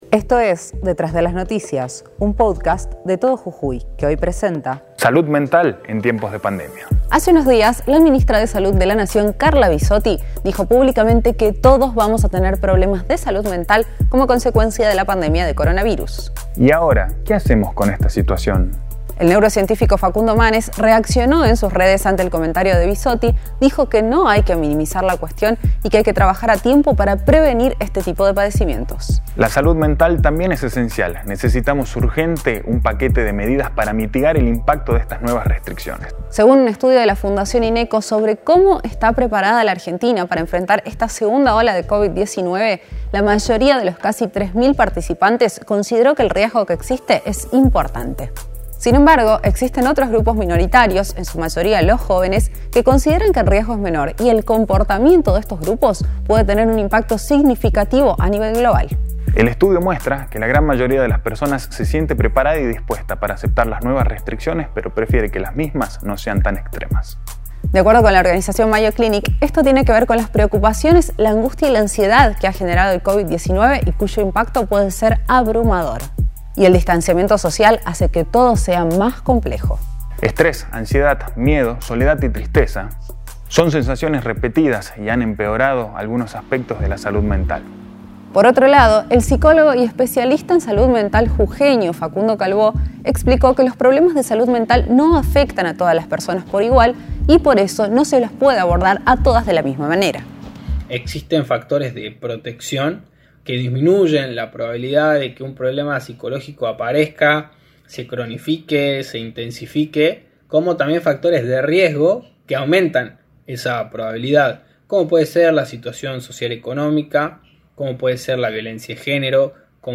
Lo analizamos con especialistas en Detrás de las noticias.